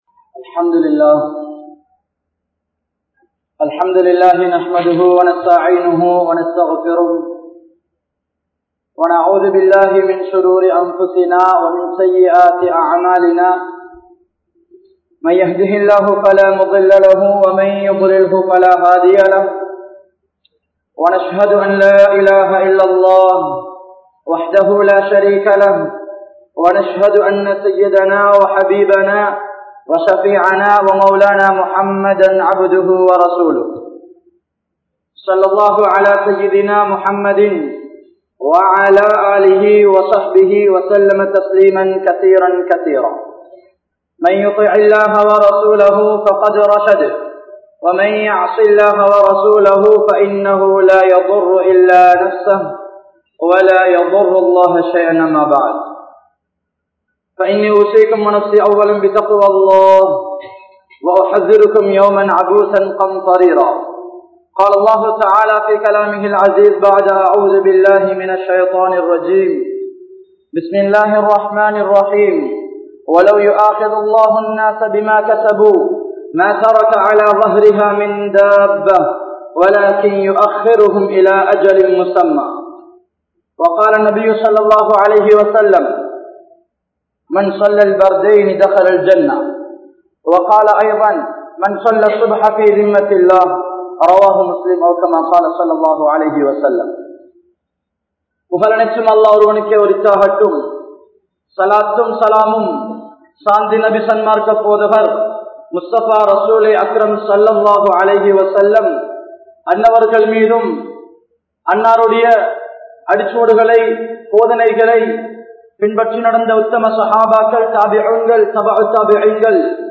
Ramalanum Subah Tholuhaium Sila Muslimkalum (ரமழானும் சுபஹ் தொழுகையும் சில முஸ்லிம்களும்) | Audio Bayans | All Ceylon Muslim Youth Community | Addalaichenai